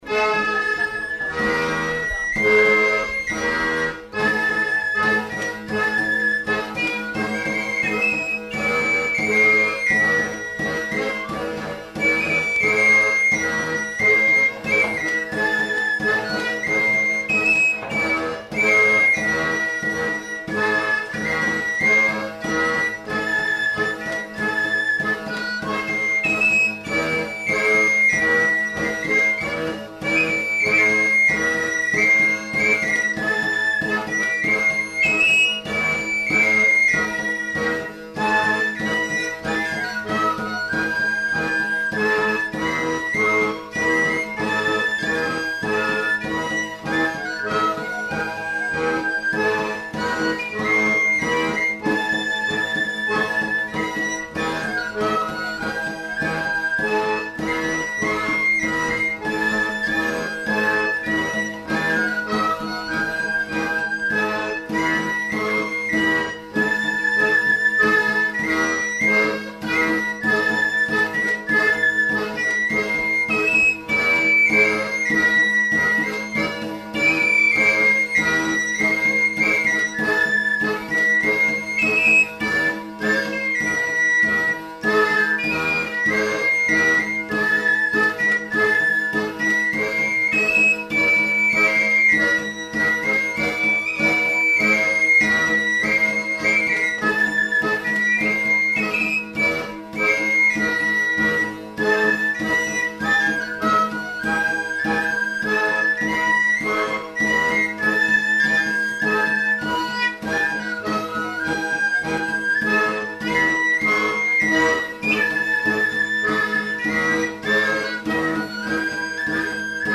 Lieu : Pyrénées-Atlantiques
Genre : morceau instrumental
Instrument de musique : flûte à trois trous ; tambourin à cordes ; accordéon diatonique